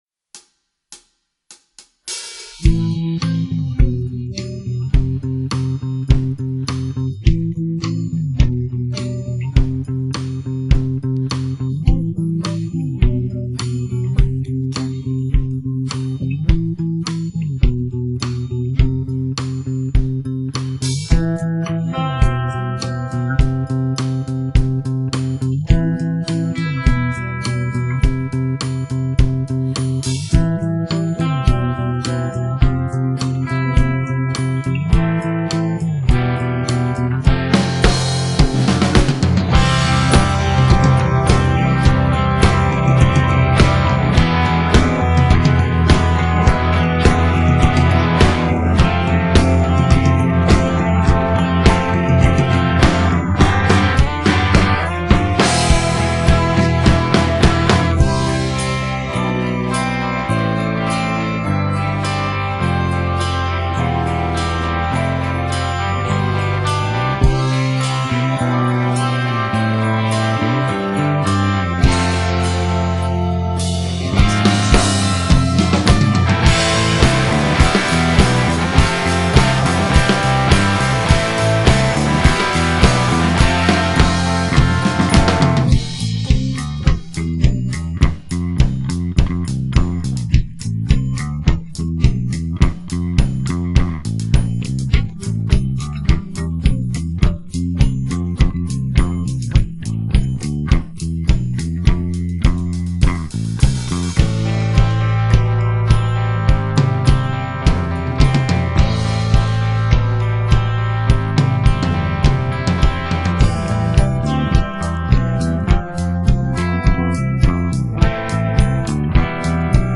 ボーカル抜きの音源はこちら